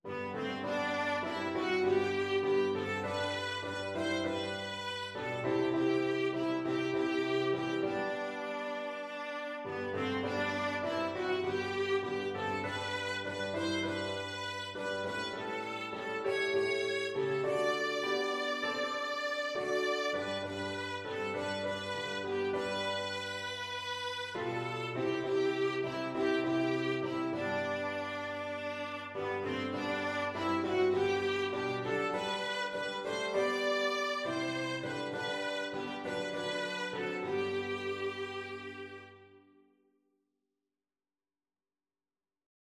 Viola
4/4 (View more 4/4 Music)
G major (Sounding Pitch) (View more G major Music for Viola )
Classical (View more Classical Viola Music)
since_jesus_came_VLA.mp3